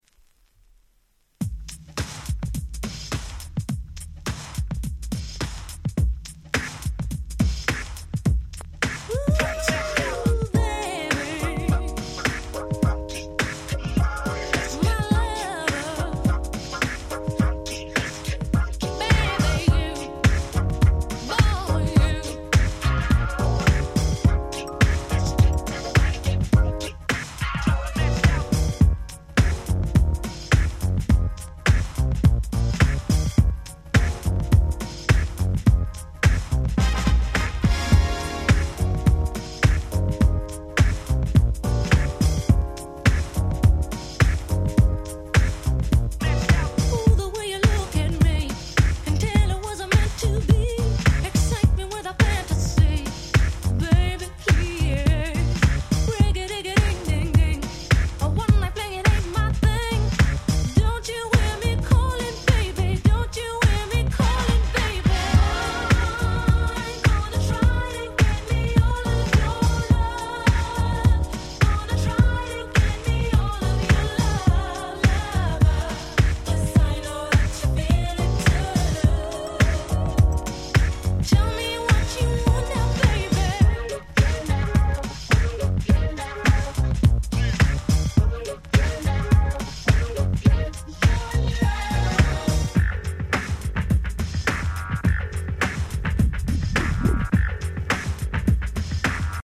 94' Nice UK R&B !!